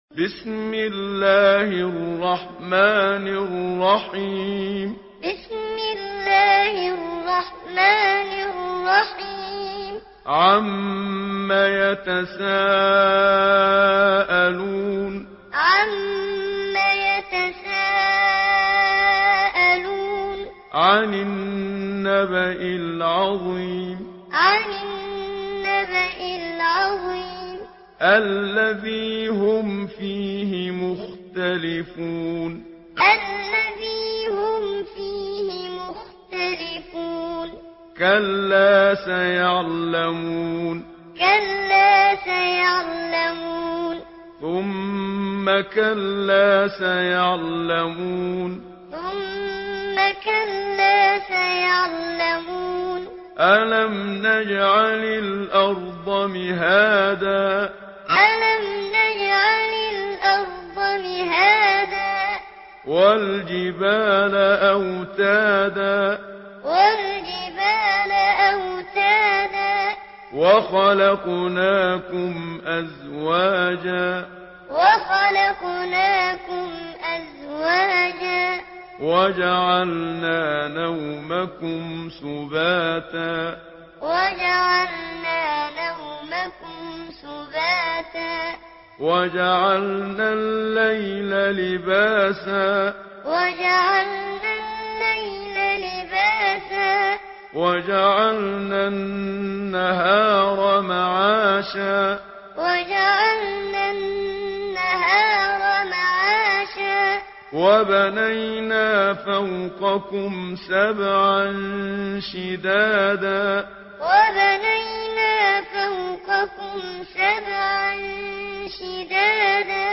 Surah আন-নাবা MP3 by Muhammad Siddiq Minshawi Muallim in Hafs An Asim narration.